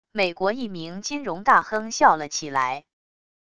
美国一名金融大亨笑了起来wav音频